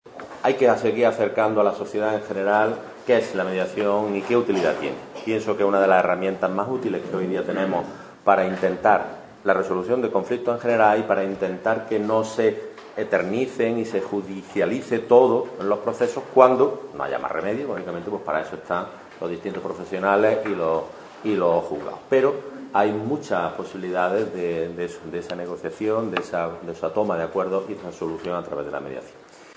han presidido en la mañana de hoy la inauguración oficial de la tercera edición de las Jornadas de Mediación que organiza la asociación "Solucion@" en colaboración con el Ayuntamiento de Antequera y en la que participan más de cien personas de diferentes ámbitos como policías, abogados, procuradores, trabajadores sociales, etc.
Cortes de voz